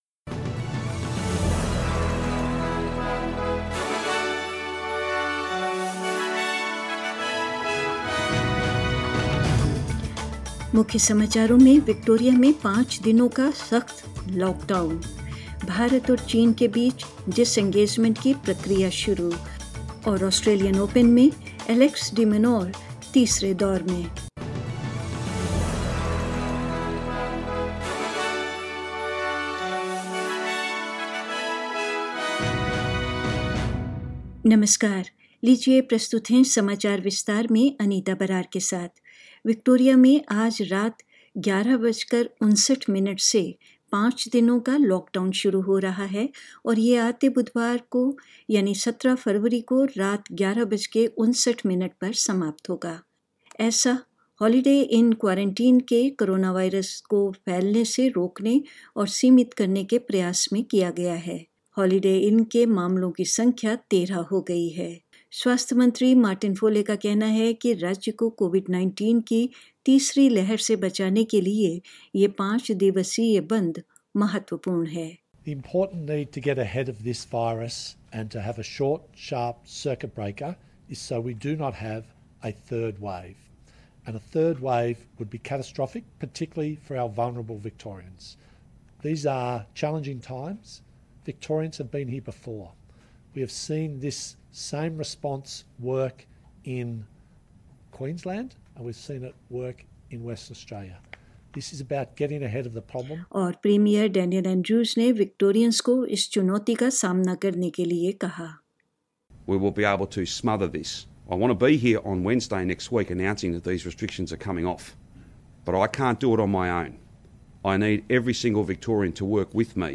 News in Hindi: Victoria to enter a five day hard lockdown